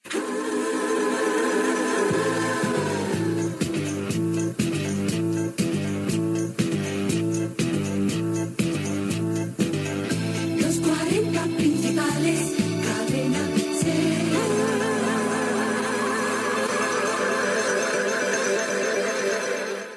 Indicatiu del programa
Musical